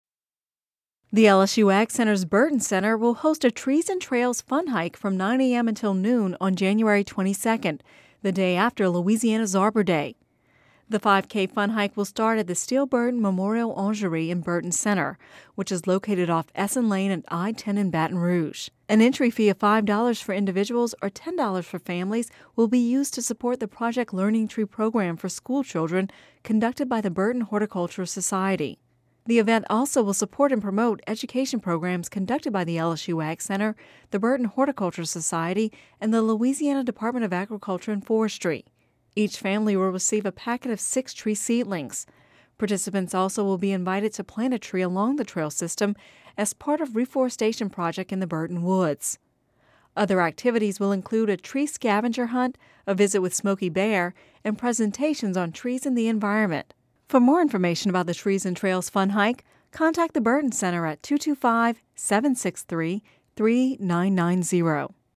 (Radio News 01/10/11) The LSU AgCenter's Burden Center will host a Trees and Trails Fun Hike from 9 a.m. until noon Jan. 22 -- the day after Louisiana’s Arbor Day.